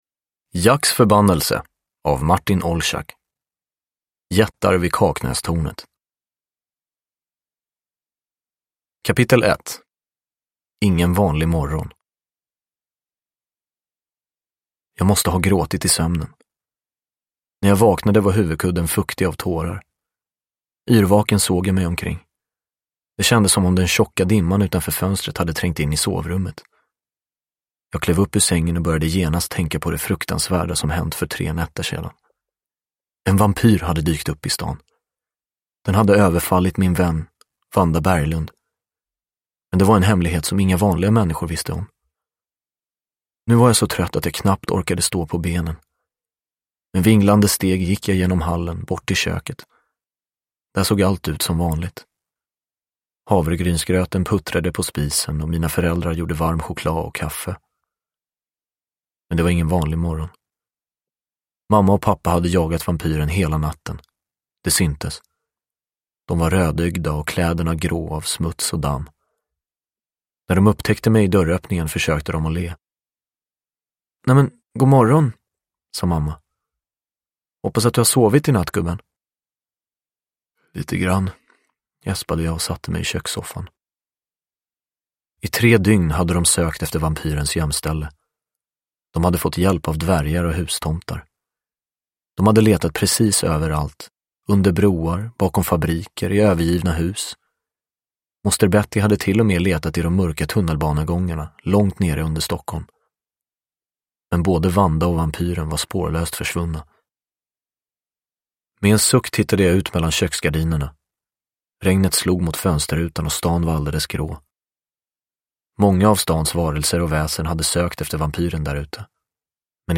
Jättar vid Kaknästornet – Ljudbok – Laddas ner
Uppläsare: Anastasios Soulis